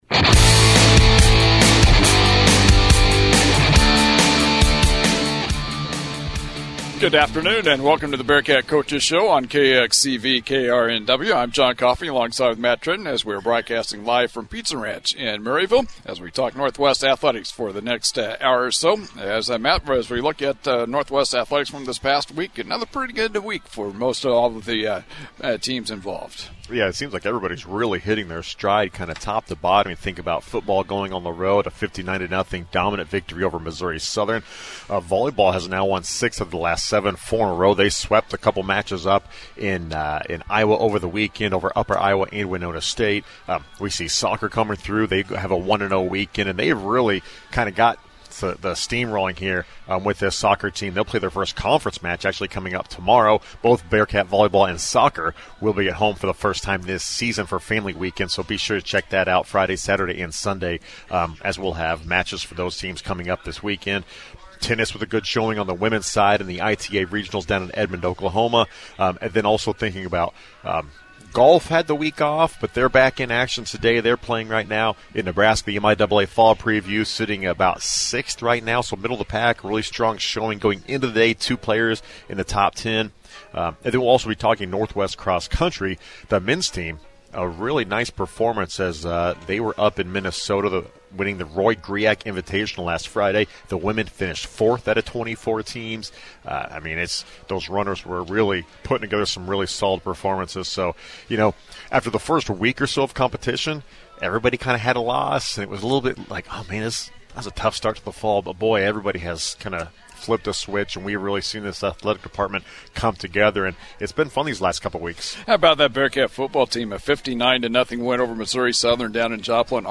September 24 Bearcat Coaches Show